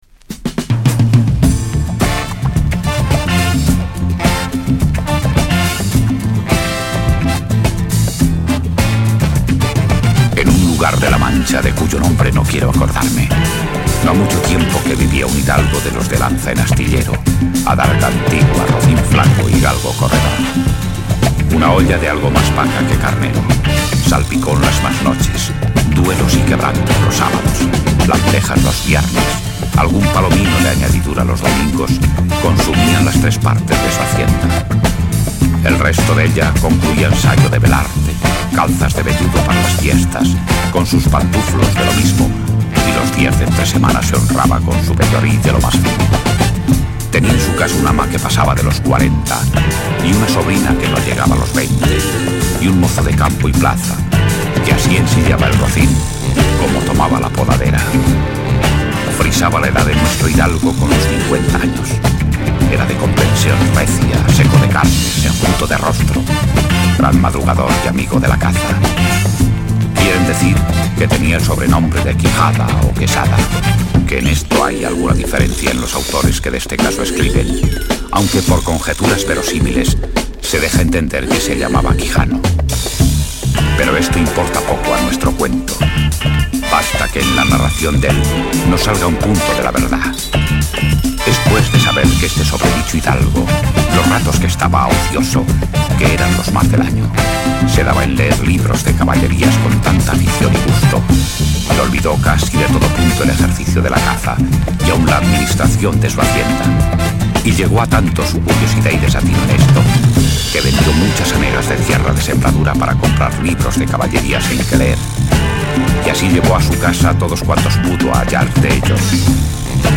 Jazz Groove spain